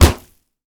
punch_grit_wet_impact_06.wav